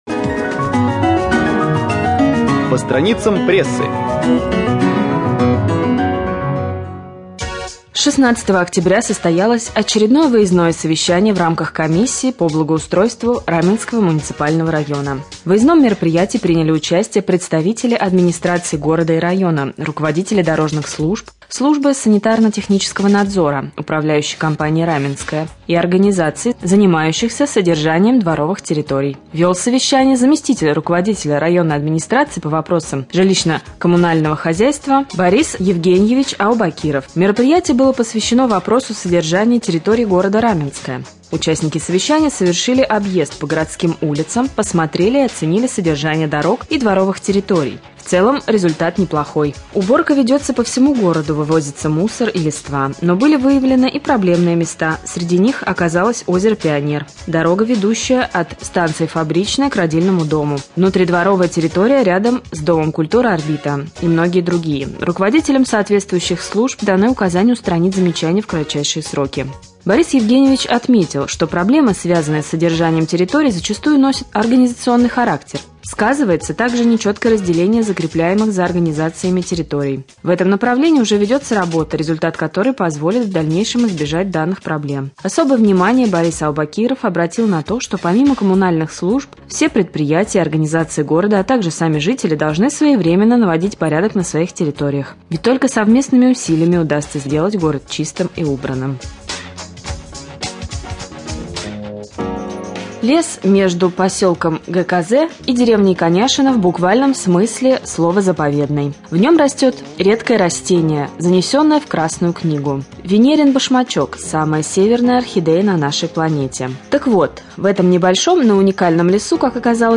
23.10.2013г в эфире раменского радио